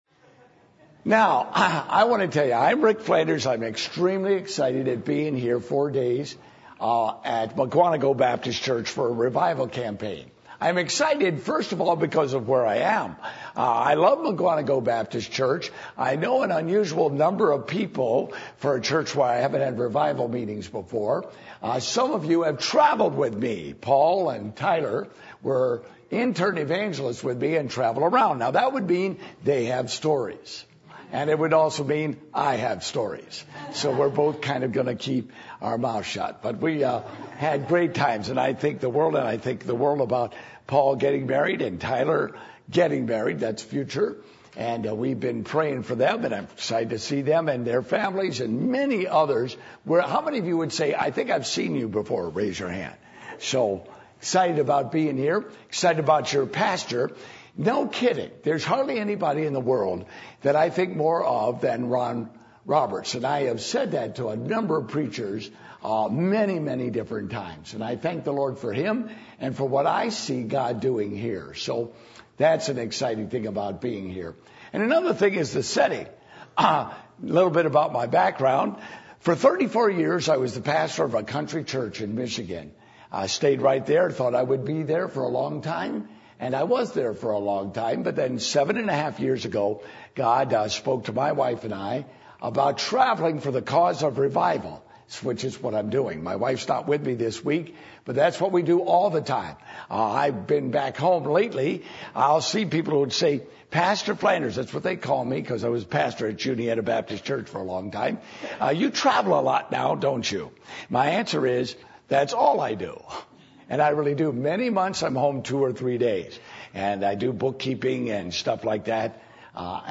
Psalm 85:1-13 Service Type: Revival Meetings %todo_render% « The Signs Of A Need For Revival Issues Between God And His People